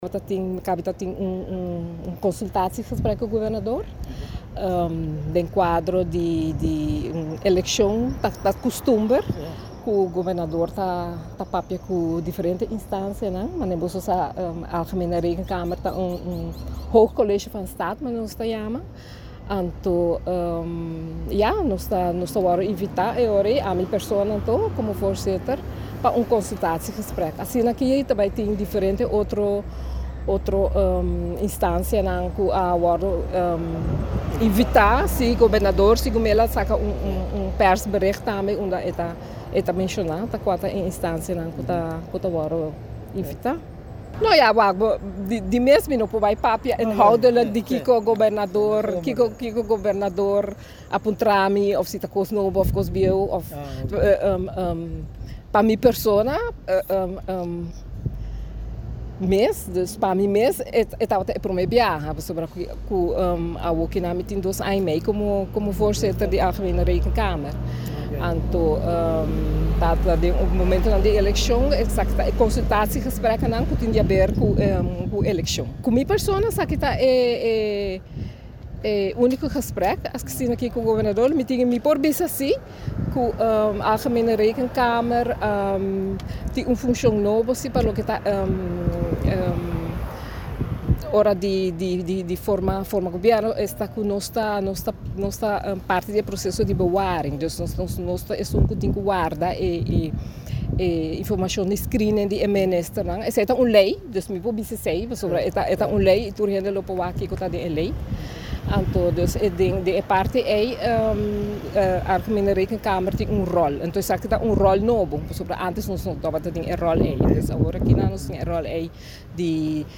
Presidente di Algemene Rekenkamer Sra. Xiomara Croes Williams ta splica un poco con e reunion a bay.